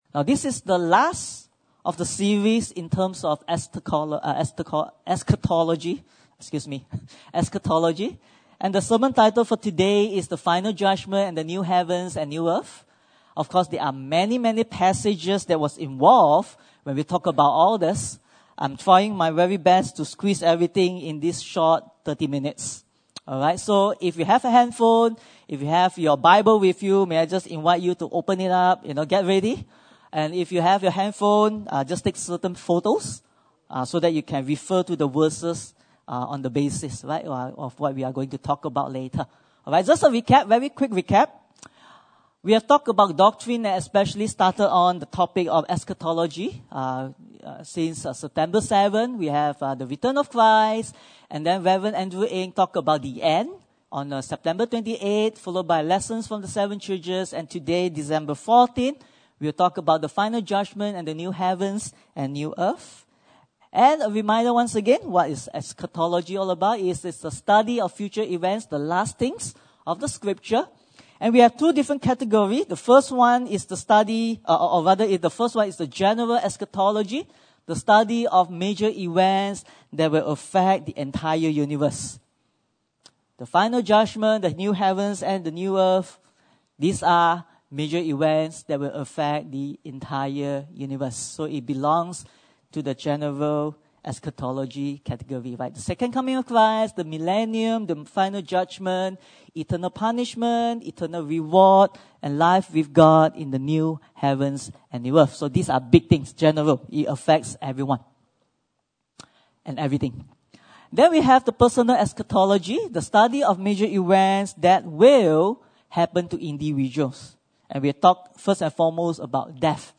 Passage: 2 Corinthians 5:10, Revelation 20:11-15 Service Type: Sunday Service